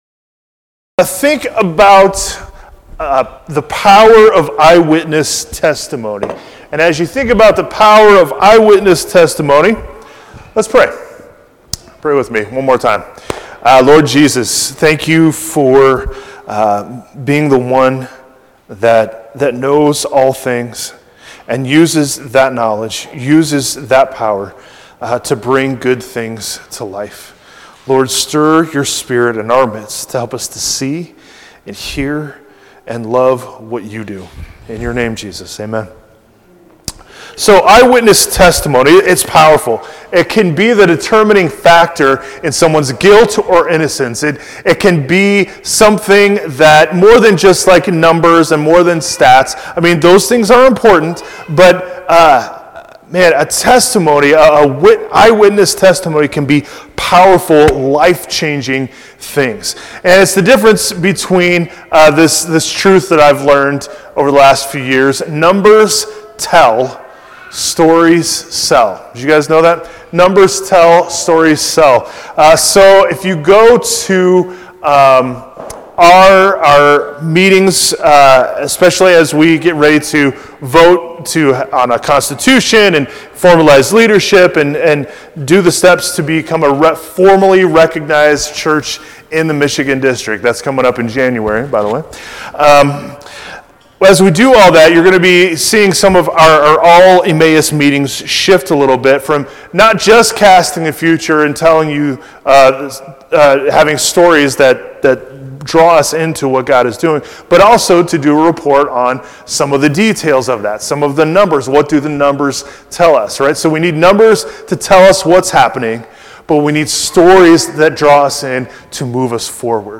Dec-7-2025-sermon.mp3